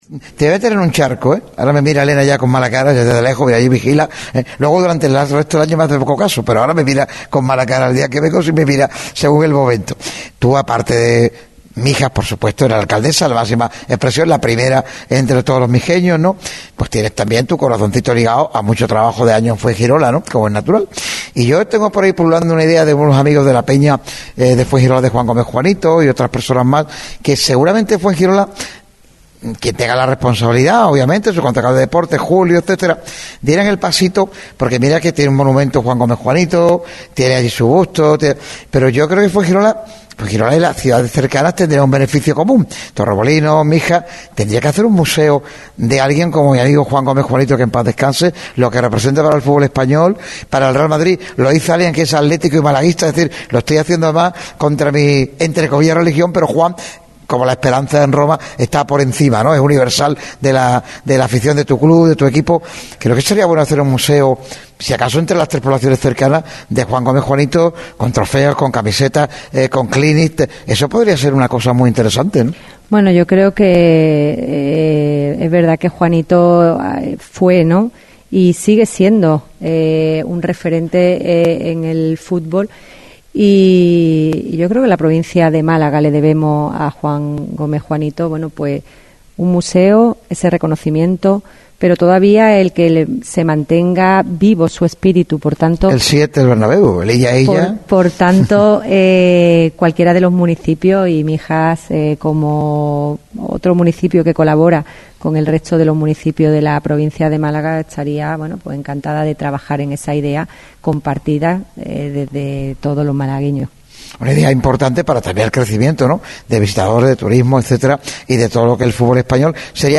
Ana Carmen Mata, alcaldesa de Mijas, pasó por el programa especial de Radio MARCA Málaga dedicado al deporte mijeño que se celebró este jueves en el salón de actos de la Tenencia de Alcaldía de la Cala de Mijas.